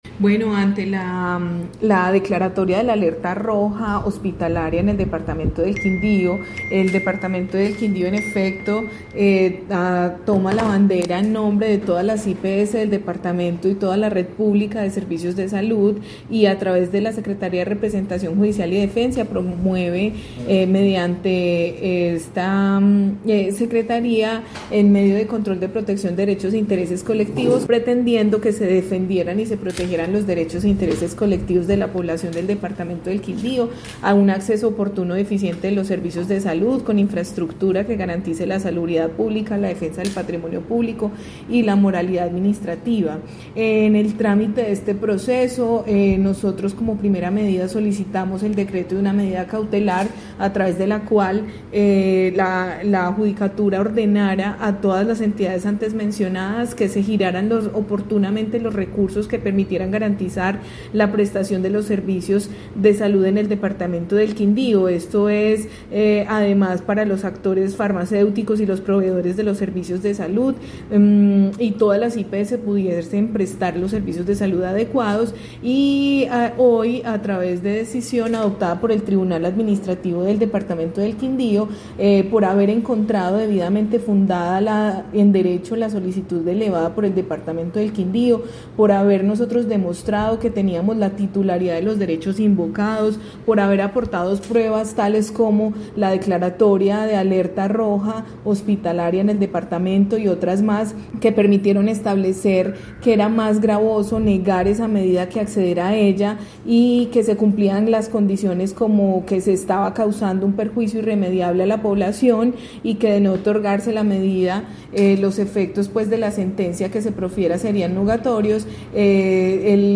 Secretaria de representación Judicial y Defensa del departamento, Isabel Lezama